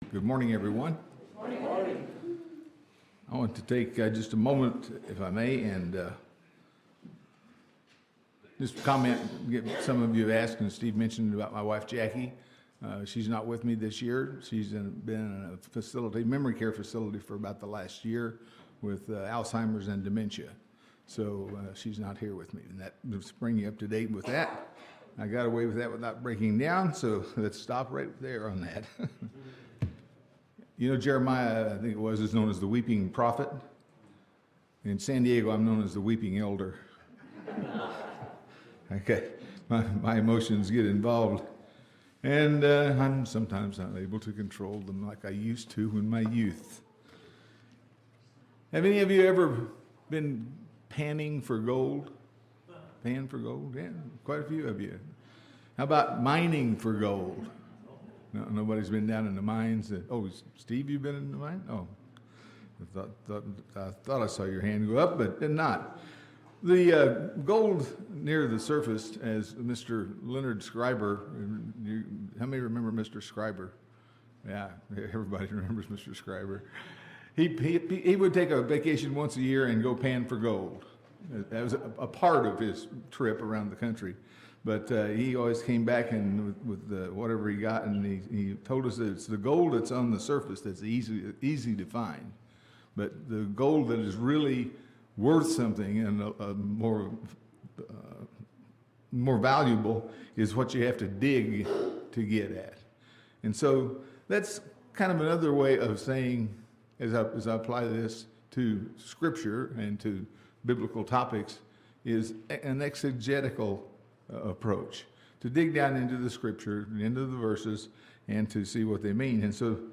This sermon explores Jesus' instruction to a young man inquiring about eternal life.